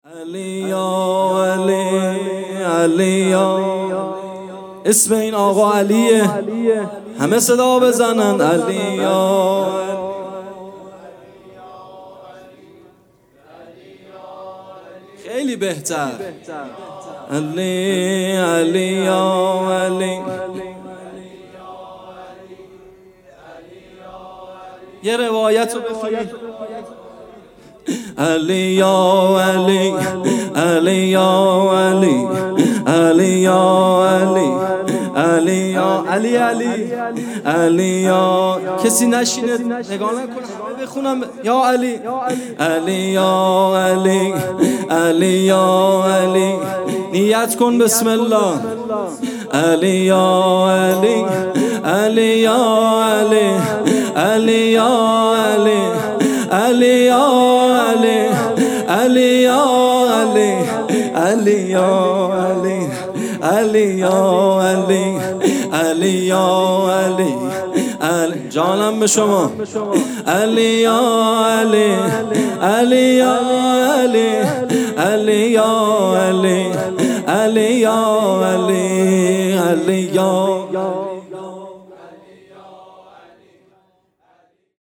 جلسه هفتگی
music-icon سرود